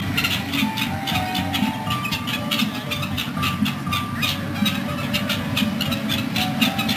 ほろほろ鳥　2007
雑音が多いです。